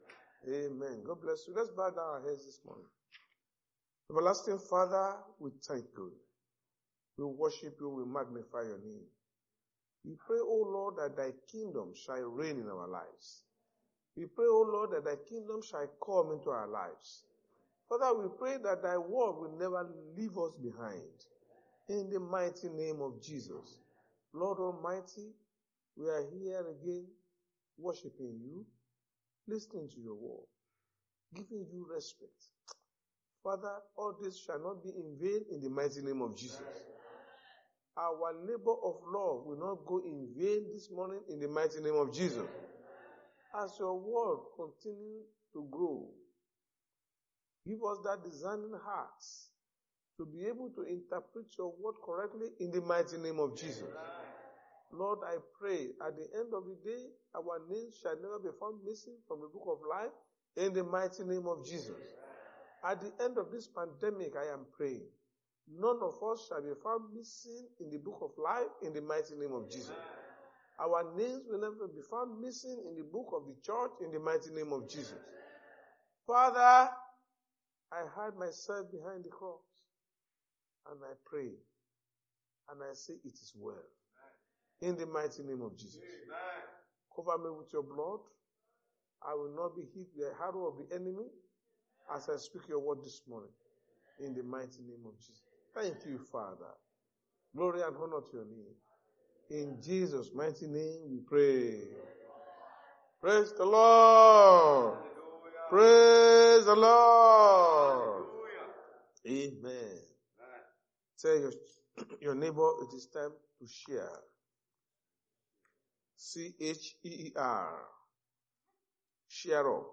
Sunday Sermon: It’s Time To Cheer Up
Service Type: Sunday Church Service